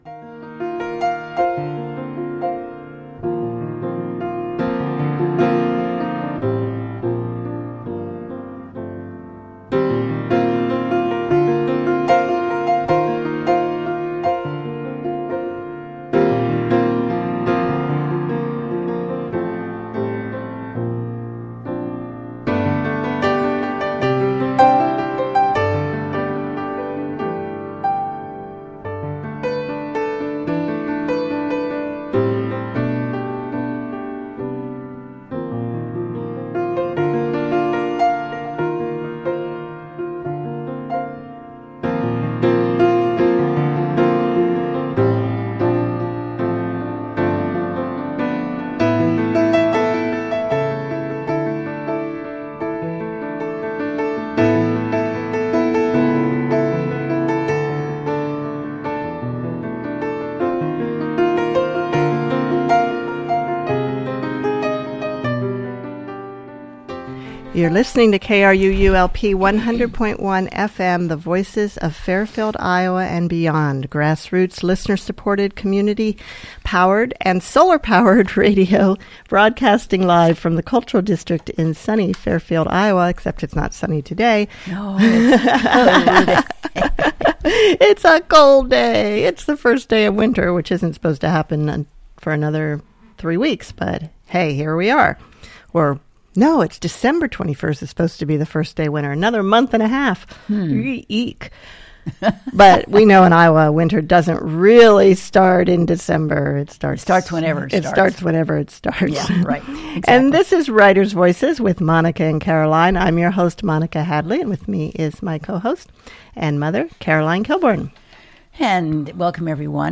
An interesting conversation and a fascinating look at the interior workings of the novelist’s mind.